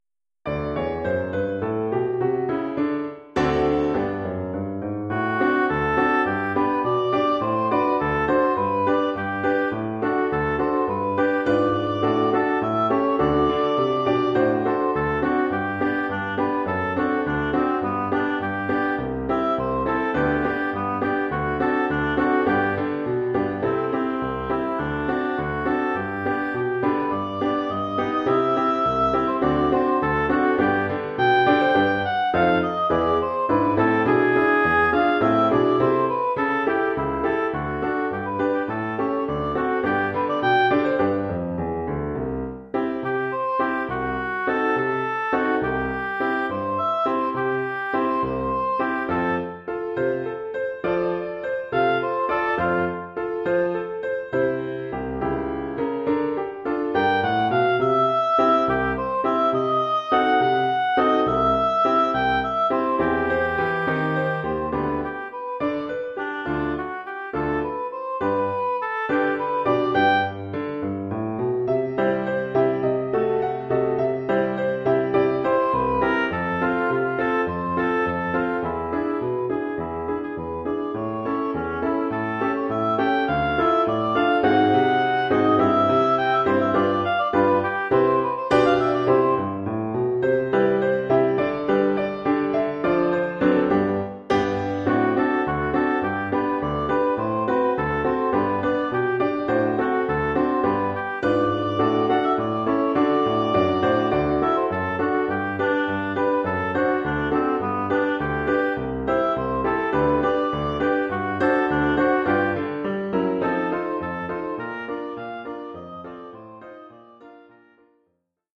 Oeuvre pour hautbois et piano.